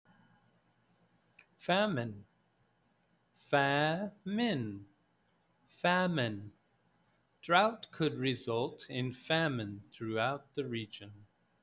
Read the word, study the definition, and listen to how the word is pronounced. Then, listen to how it is used in the sample sentences.